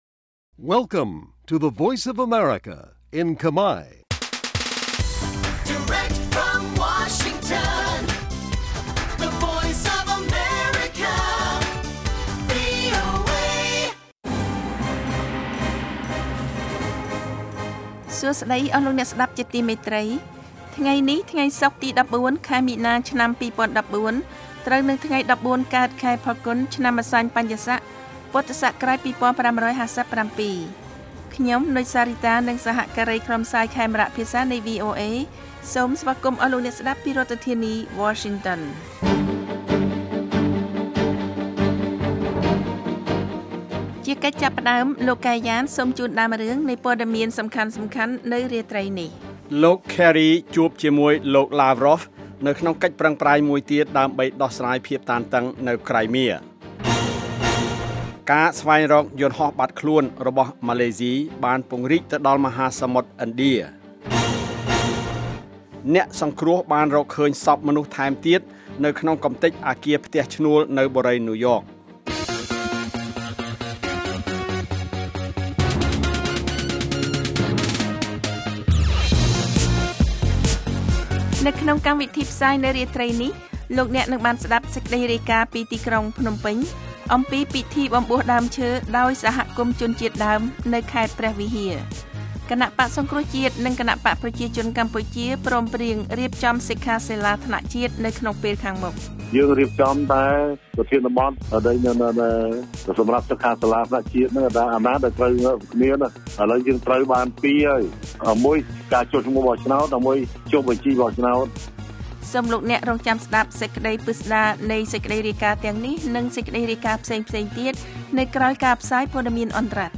នេះជាកម្មវិធីផ្សាយប្រចាំថ្ងៃតាមវិទ្យុ ជាភាសាខ្មែរ រយៈពេល ៦០ នាទី ដែលផ្តល់ព័ត៌មានអំពីប្រទេសកម្ពុជានិងពិភពលោក ក៏ដូចជាព័ត៌មានពិពណ៌នា ព័ត៌មានអត្ថាធិប្បាយ កម្មវិធីតន្ត្រី កម្មវិធីសំណួរនិងចម្លើយ កម្មវិធីហៅចូលតាមទូរស័ព្ទ និង បទវិចារណកថា ជូនដល់អ្នកស្តាប់ភាសាខ្មែរនៅទូទាំងប្រទេសកម្ពុជា។ កាលវិភាគ៖ ប្រចាំថ្ងៃ ម៉ោងផ្សាយនៅកម្ពុជា៖ ៨:៣០ យប់ ម៉ោងសកល៖ ១៣:០០ រយៈពេល៖ ៦០នាទី ស្តាប់៖ សំឡេងជា MP3